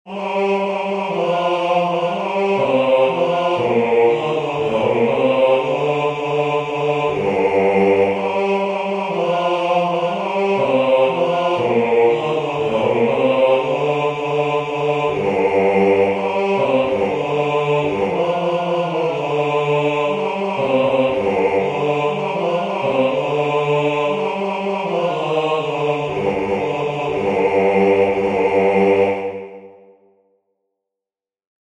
Chanson_a_boire-Basse.mp3